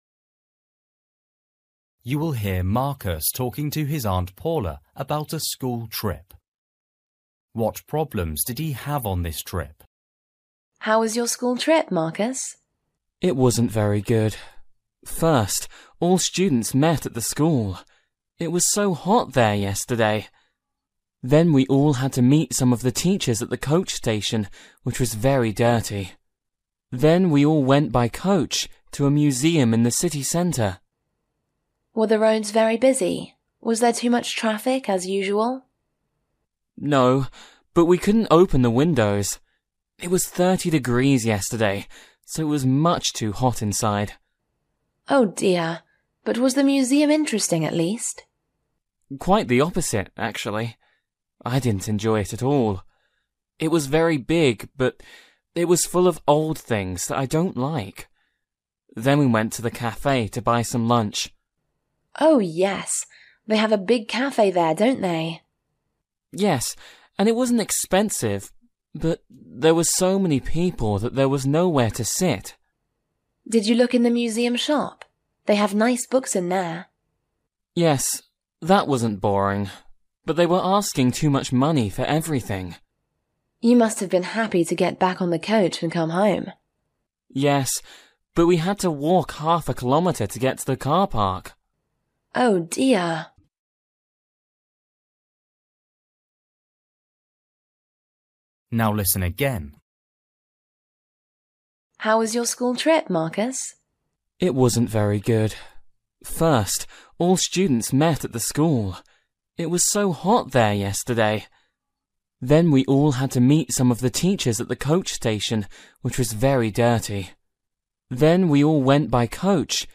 You will hear Marcus talking to his Aunt Paula about a school trip.